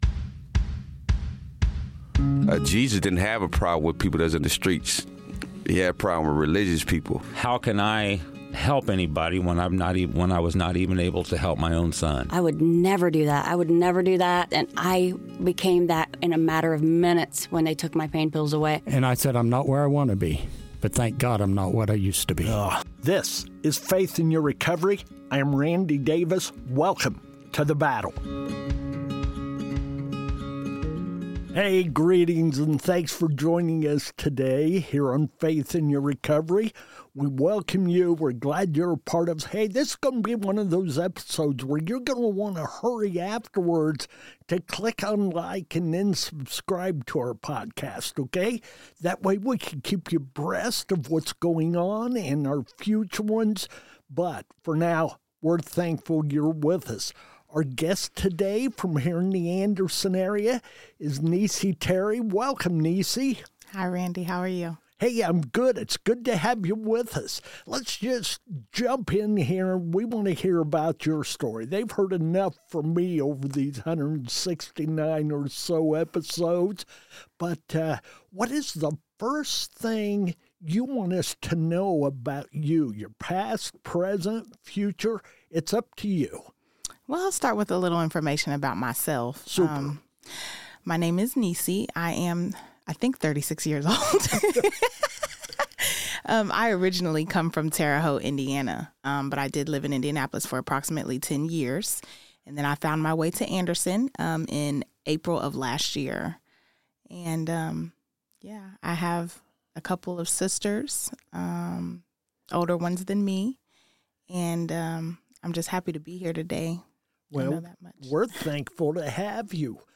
Join us we interview inmates about their addiction and recovery experiences. This episode was produced from inside the Jay County Security Center.